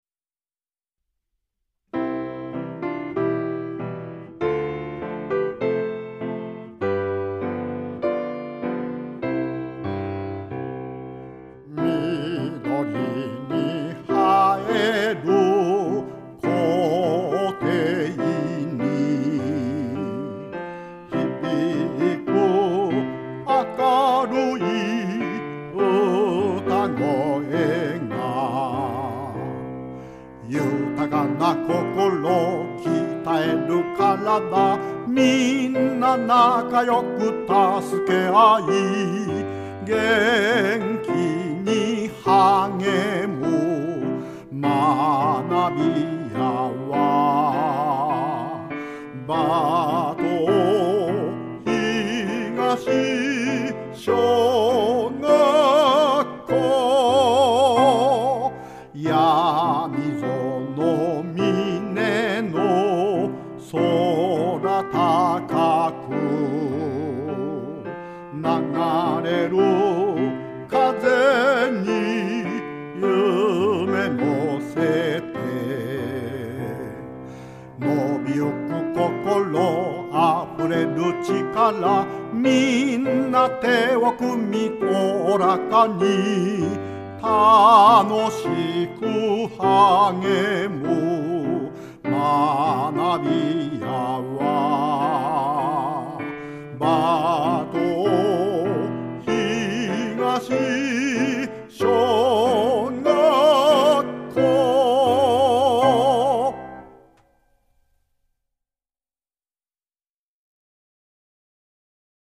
校歌
ピアノ